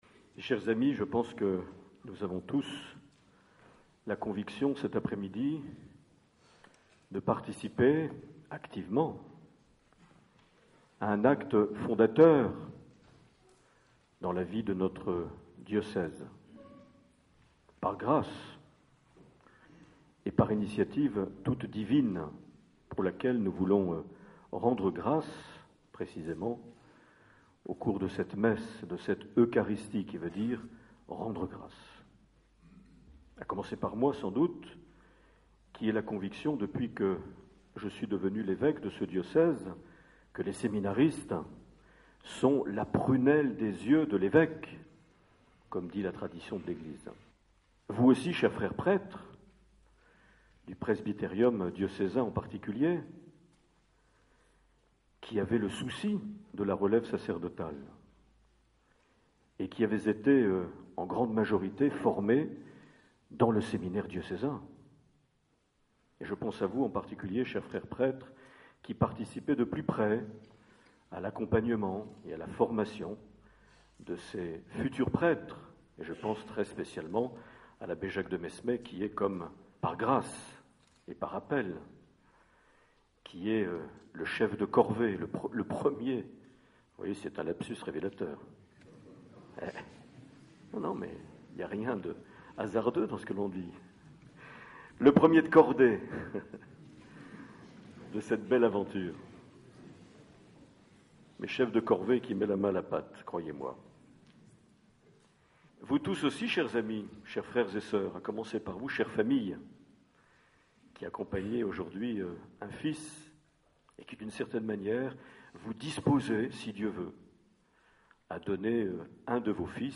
11 septembre 2011 - Bayonne ancien couvent des Capucins - Inauguration des locaux du séminaire diocésain
Accueil \ Emissions \ Vie de l’Eglise \ Evêque \ Les Homélies \ 11 septembre 2011 - Bayonne ancien couvent des Capucins - Inauguration des (...)
Une émission présentée par Monseigneur Marc Aillet